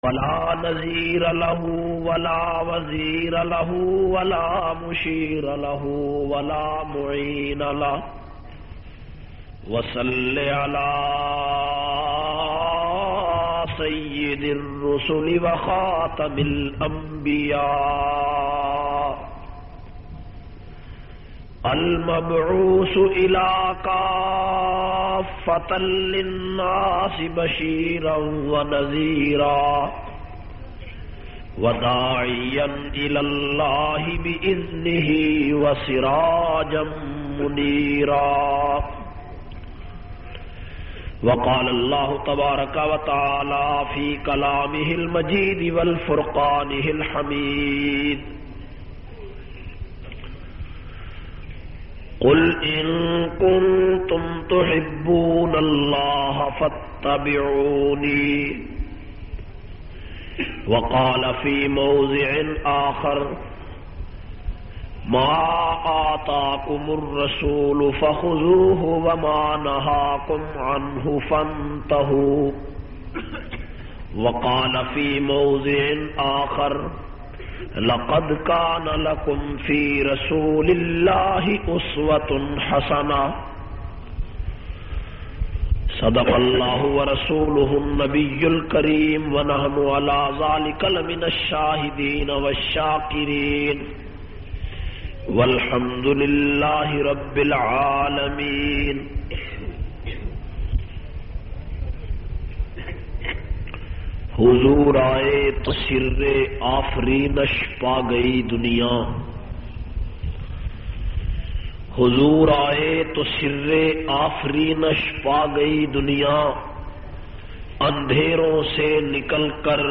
04- Seerat un Nabi 12 Rabi ul awwal masjad taqwa jhang.mp3